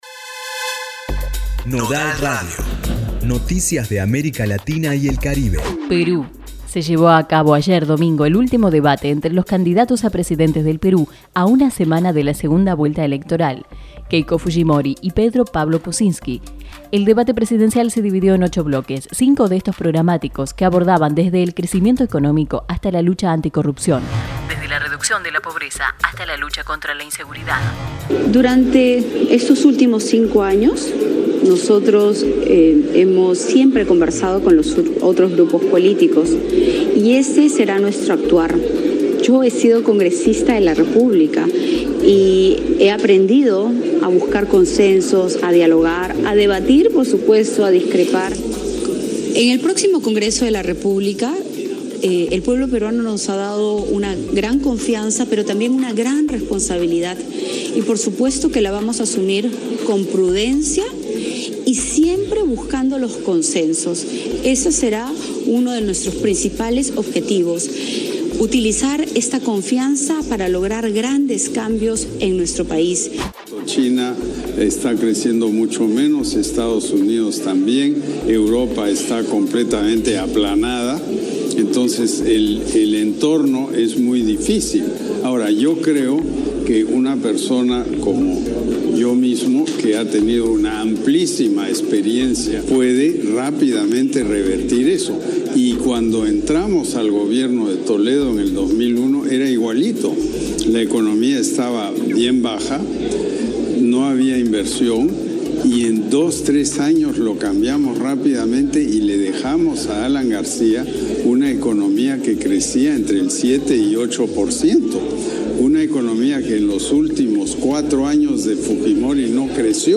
Escucha el informativo coproducido por La Tecno y Nodal y enterate todo lo que pasa en América Latina y El Caribe: